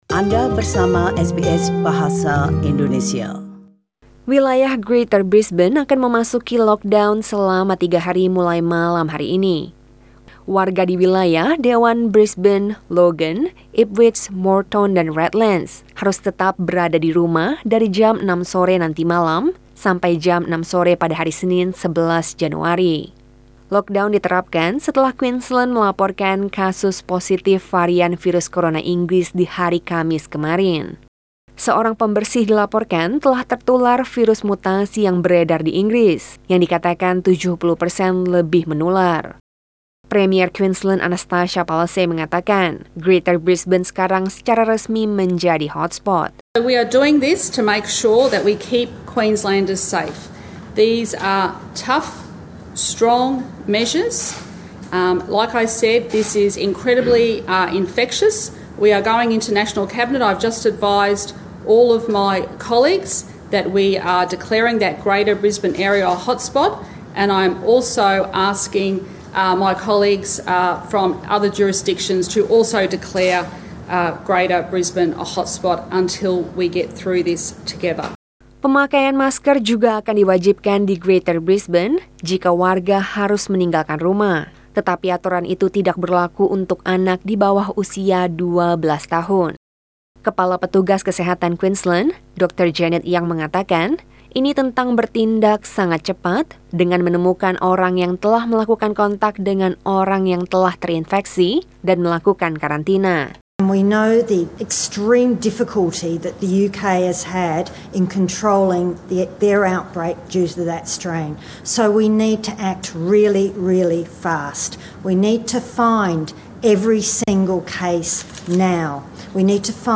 SBS Radio News in Bahasa Indonesia - 8 January 2021
Warta Berita Radio SBS Program Bahasa Indonesia.